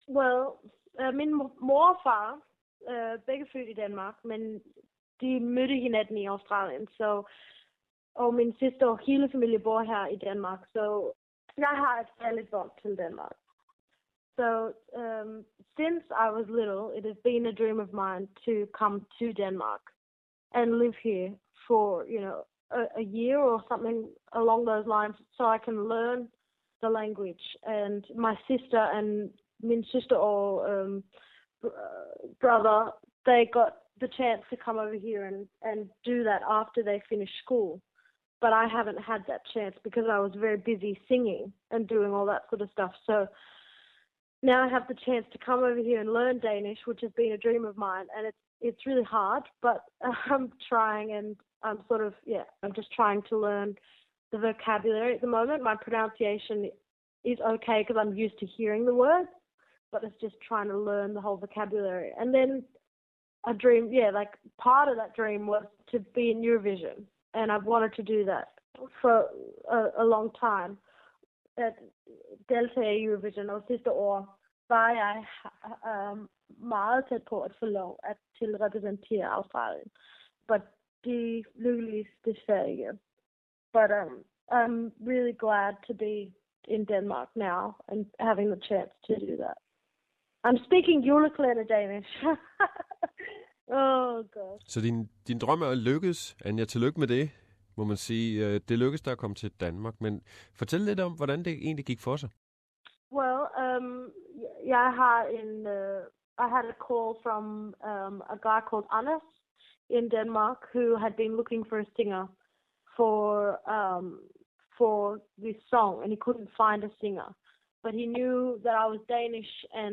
In the interview, which is conducted in Danish language, Anja Nissen initially talks about her Danish background.